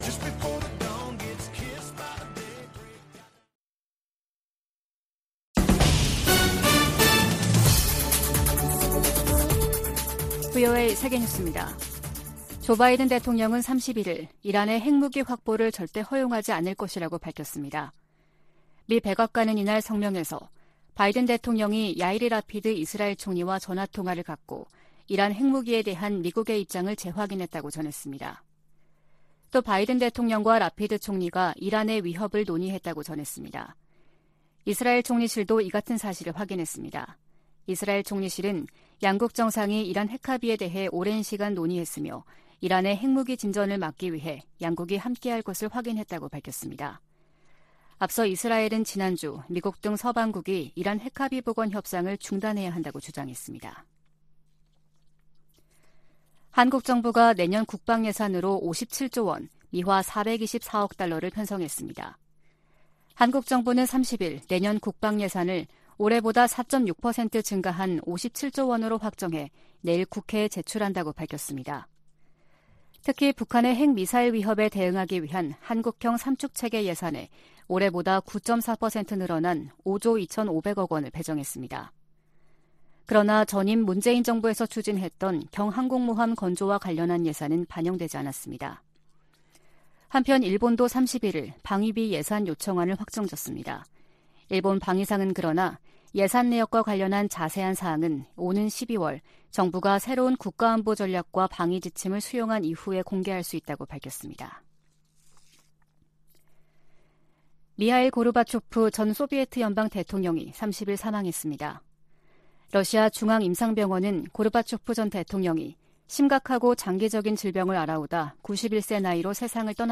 VOA 한국어 아침 뉴스 프로그램 '워싱턴 뉴스 광장' 2022년 9월 1일 방송입니다. 북한이 7차 핵실험을 할 경우 미국은 한국 등 역내 동맹국의 안보를 위해 추가 행동에 나설 것이라고 백악관이 시사했습니다. 북한이 핵무기 능력 고도화에 따라 생화학무기와 사이버 역량을 공격적으로 활용할 가능성이 커졌다는 분석이 나왔습니다. 북한이 인도의 민간단체에 식량 지원을 요청한 사실은 식량 사정의 심각성을 반영하는 것이라는 분석이 나오고 있습니다.